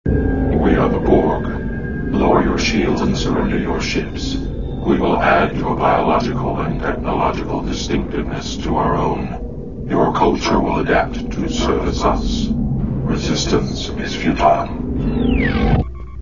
national anthem